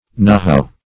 nohow - definition of nohow - synonyms, pronunciation, spelling from Free Dictionary